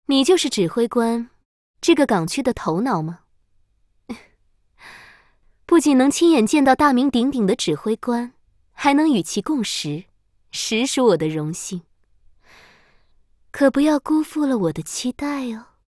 贡献 ） 协议：Copyright，人物： 碧蓝航线:镇海语音 2022年5月27日